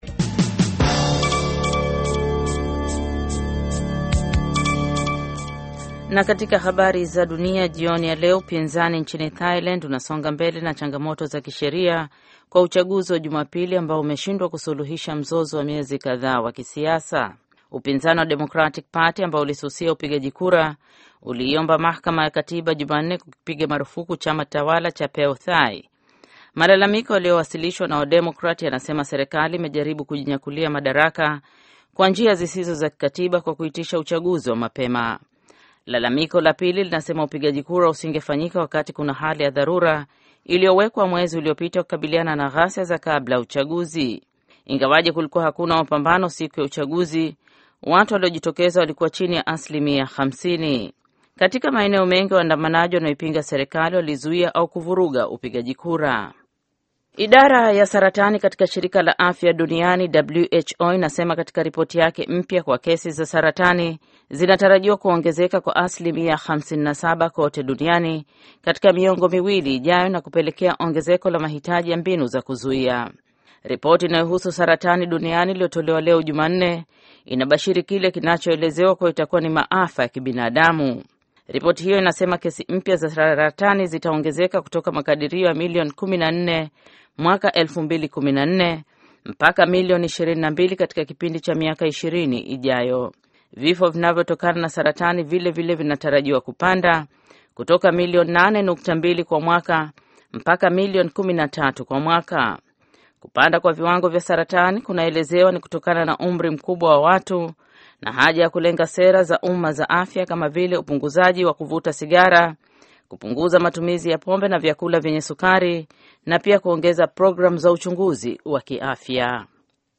Taarifa ya Habari VOA Swahili - 6:54